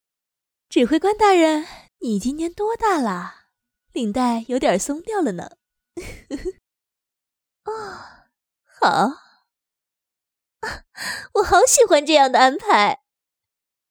女声
英雄联盟角色模仿-29豹女